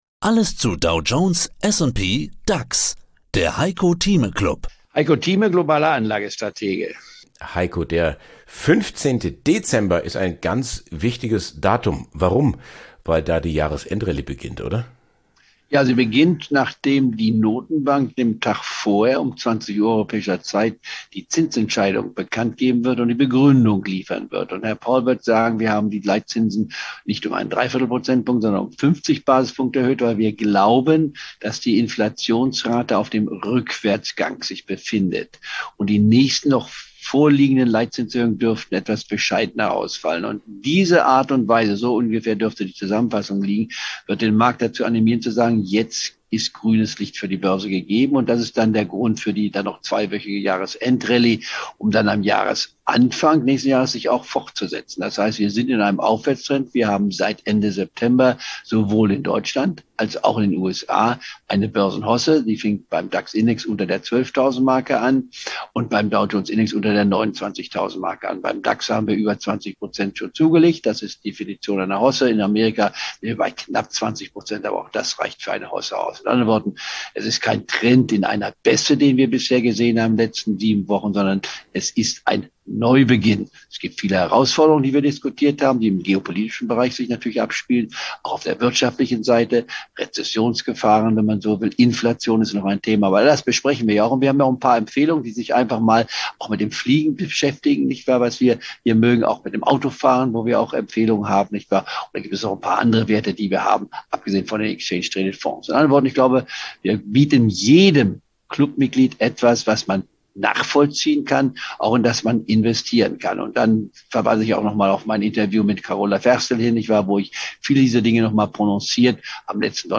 Im Heiko Thieme Club hören Sie ein mal wöchentlich ein exklusives Interview zum aktuellen Börsengeschehen, Einschätzung der Marktlage, Erklärungen wie die Börse funktioniert oder Analysen zu einzelnen Aktienwerten.